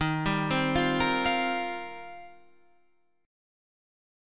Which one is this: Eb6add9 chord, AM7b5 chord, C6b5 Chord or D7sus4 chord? Eb6add9 chord